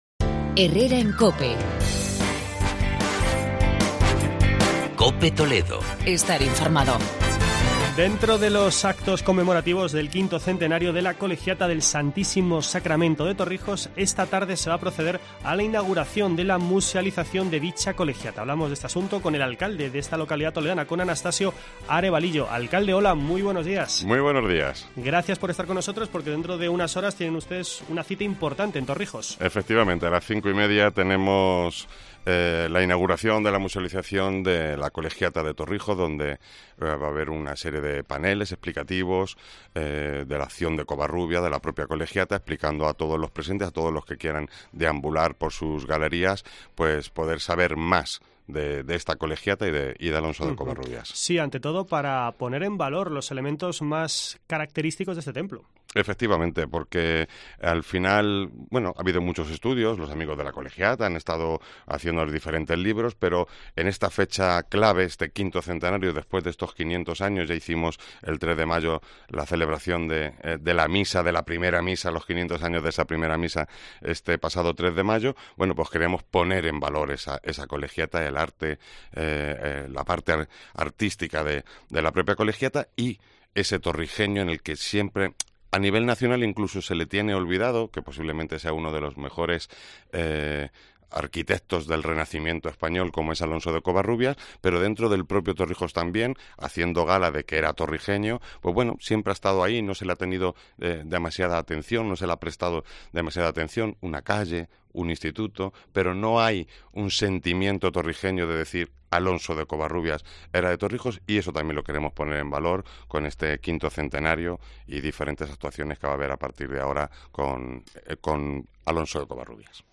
Entrevista con Anastasio Arevalillo, alcalde de Torrijos